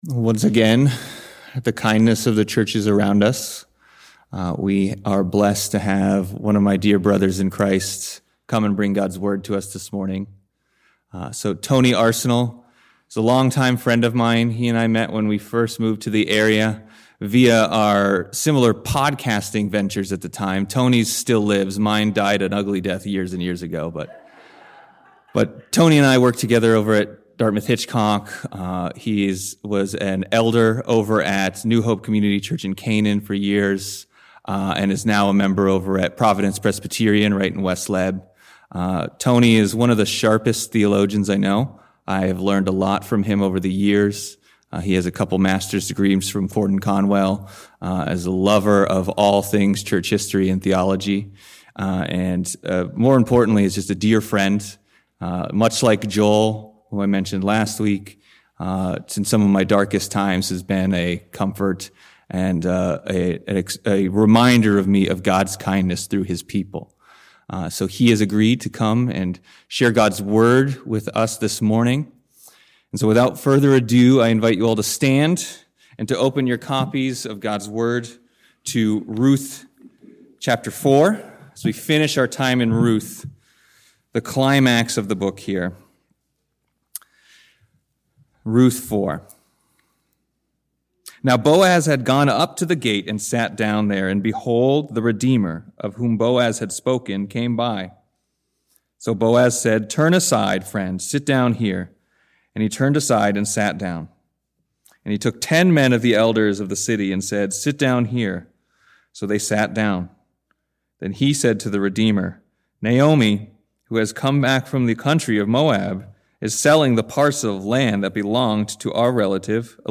The sermon examines the legal nature of Boaz’s redemption, comparing it to Christ’s forensic justification of believers.